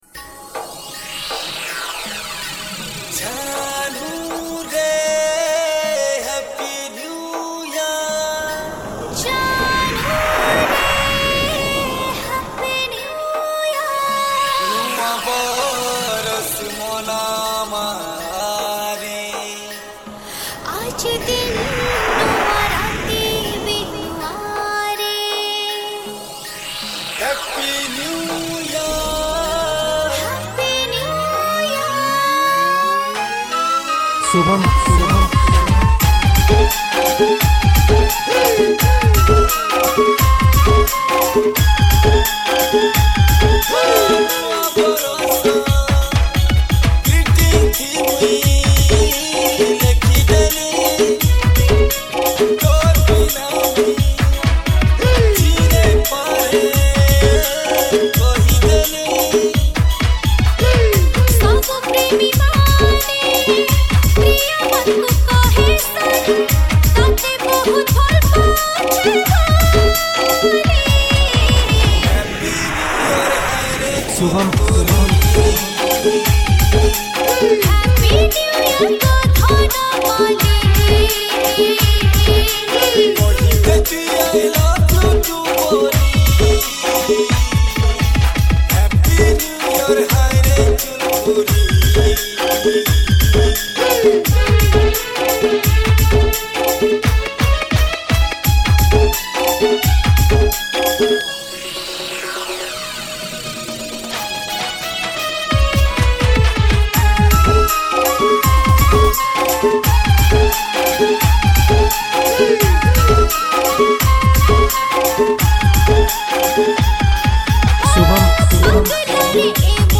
New Year Special Dj Remix